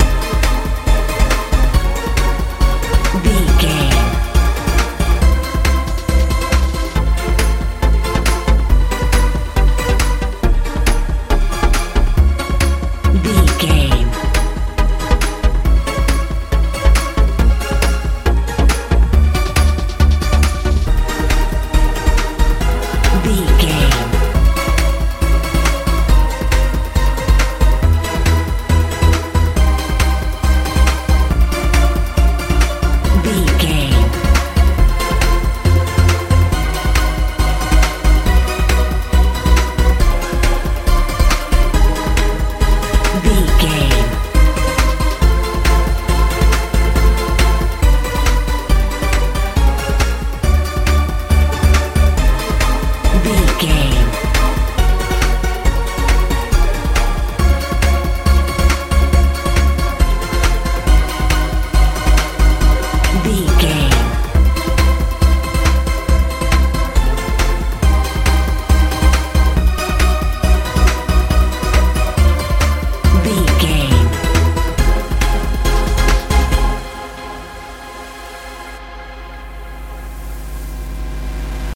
modern pop feel
Ionian/Major
A♭
groovy
funky
synthesiser
bass guitar
drums
80s